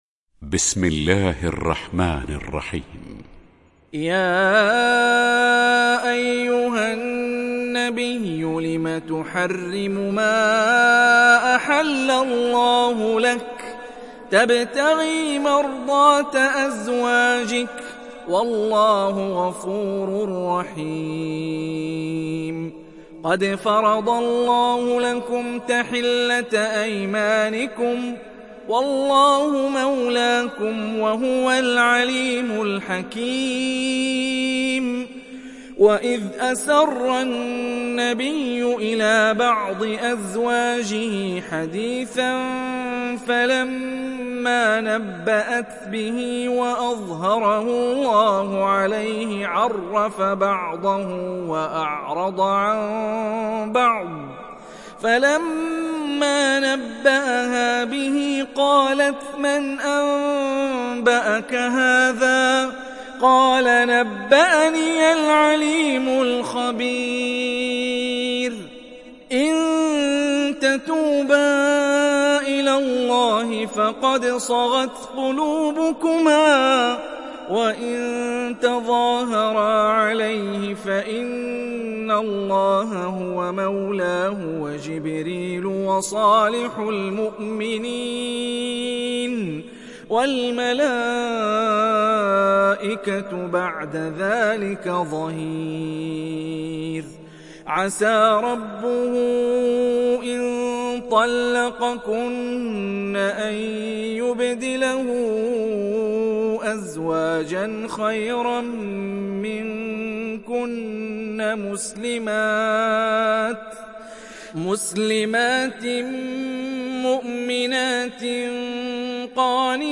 Sourate At Tahrim Télécharger mp3 Hani Rifai Riwayat Hafs an Assim, Téléchargez le Coran et écoutez les liens directs complets mp3